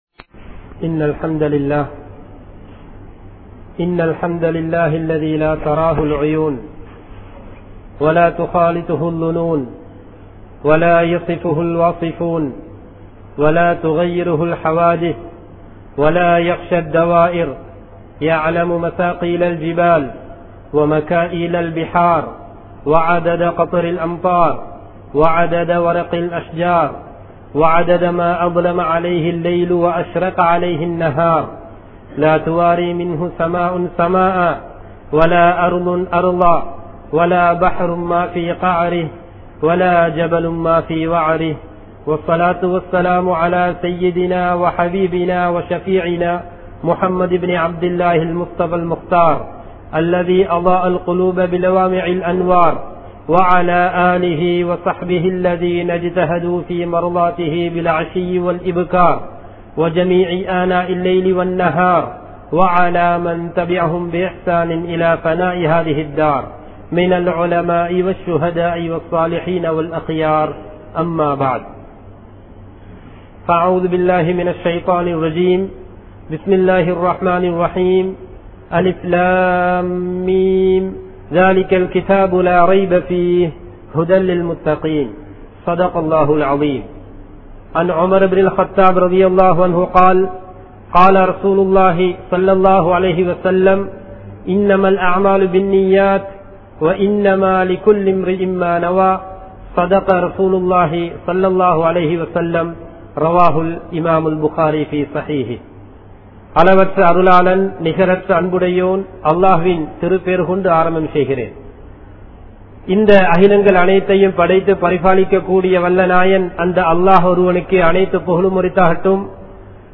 Naam Samoohaththitku Seiya Veandiyavai (நாம் சமூகத்திற்கு செய்ய வேண்டியவை) | Audio Bayans | All Ceylon Muslim Youth Community | Addalaichenai